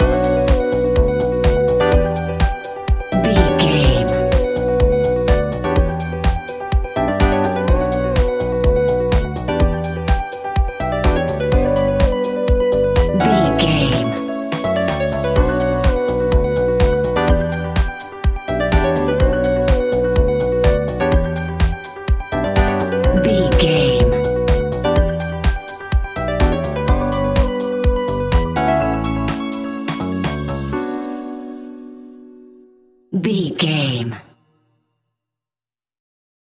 Aeolian/Minor
groovy
energetic
hypnotic
smooth
electric guitar
drum machine
synthesiser
electric piano
bass guitar
funky house
deep house
nu disco